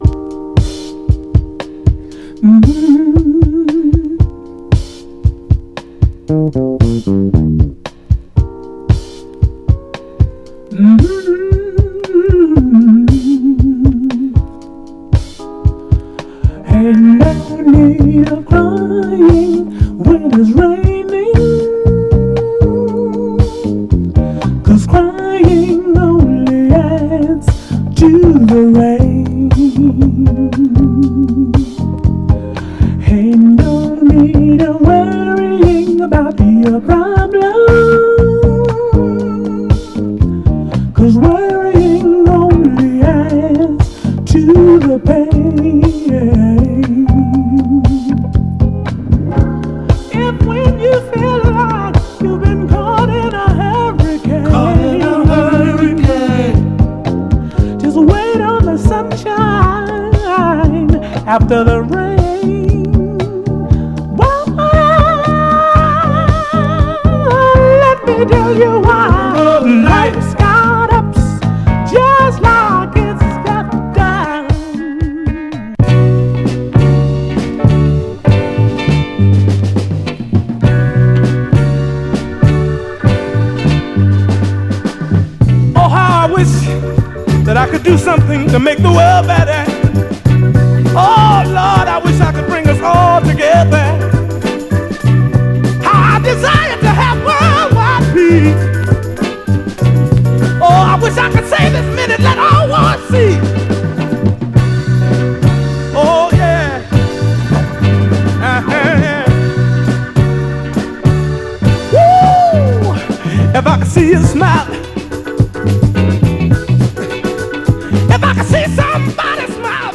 デトロイト発兄弟ゴスペルソウル・トリオ
とてつもなく柔らかな甘茶メロウ・ソウルでかなりオススメ！
※試聴音源は実際にお送りする商品から録音したものです※